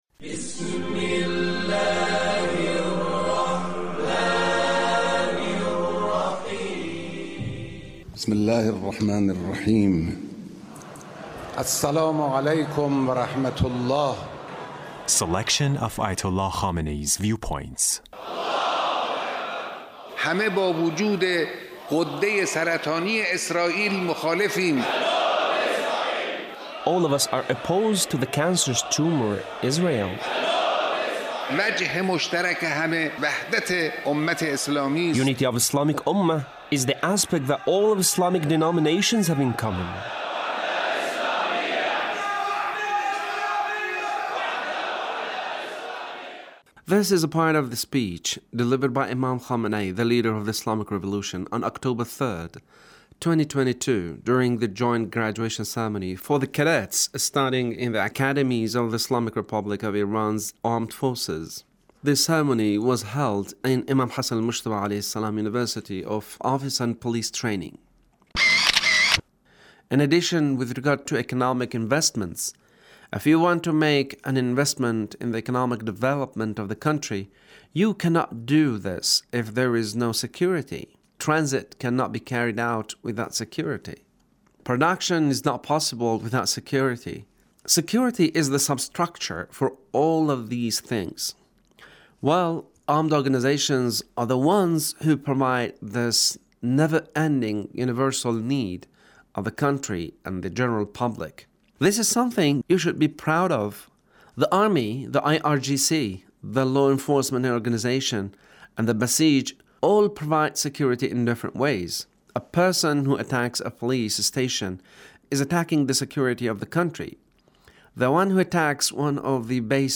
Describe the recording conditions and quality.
Leader's Speech on Graduation ceremony of Imam Hassan Mojtaba University